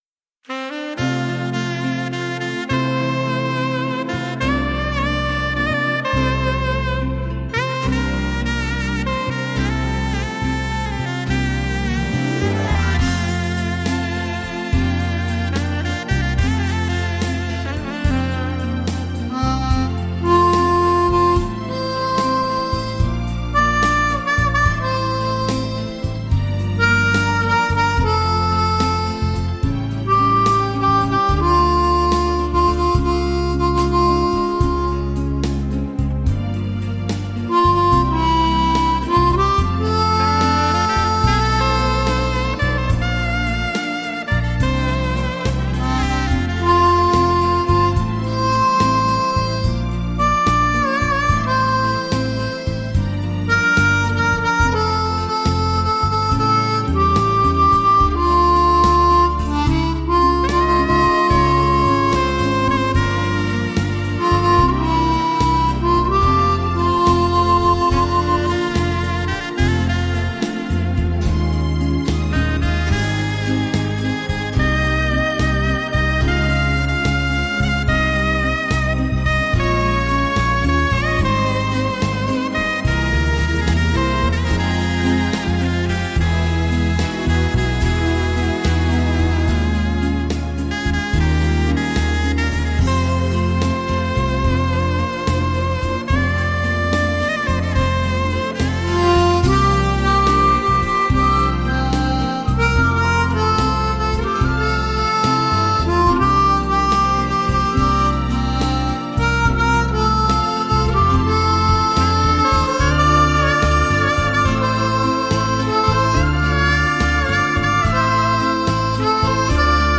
Mundharmonikaspieler